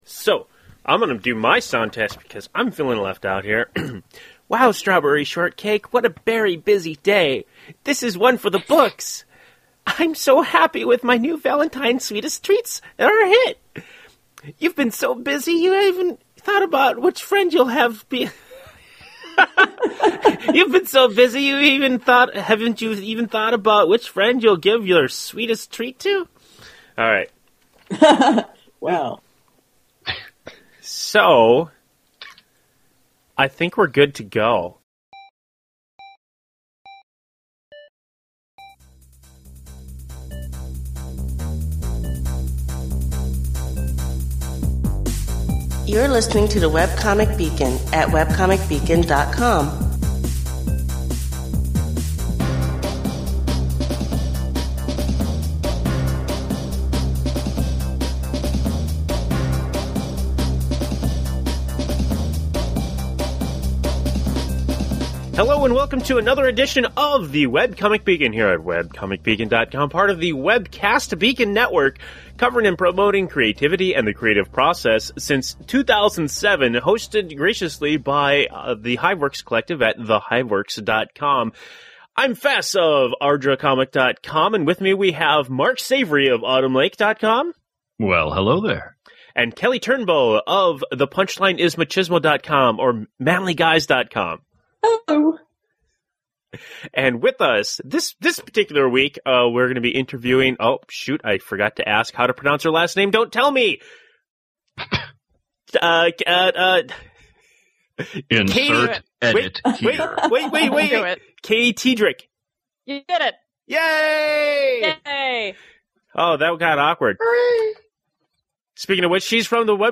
Webcomic Beacon #258: Interview